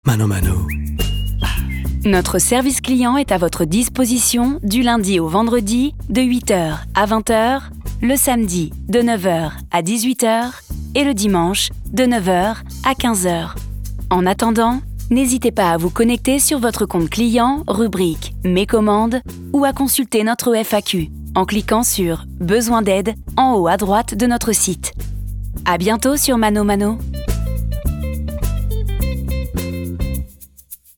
Attente téléphonique pour le compte de ManoMano, entreprise française du secteur du commerce en ligne spécialisée dans le domaine du bricolage et du jardinage.